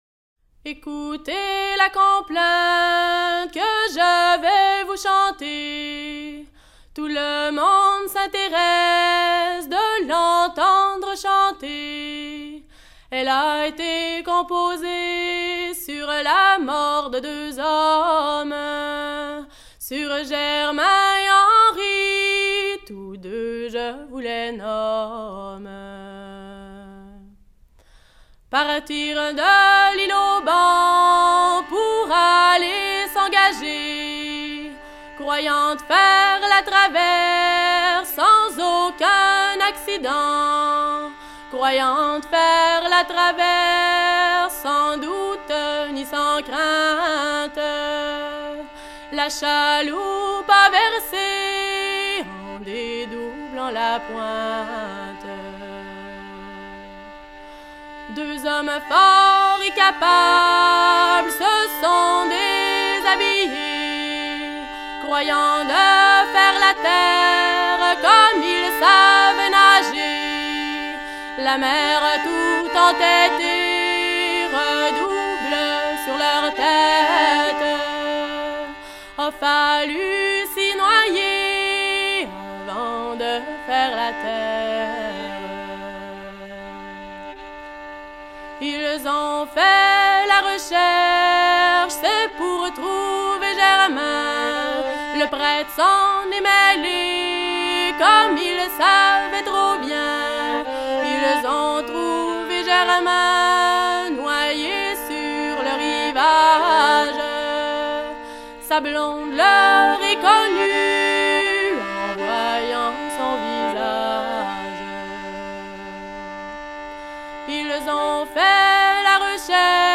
Incipit du couplet Ecoutez la complainte / Que je vais vous chanter /
Genre strophique